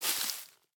snapshot / assets / minecraft / sounds / block / azalea_leaves / step2.ogg
step2.ogg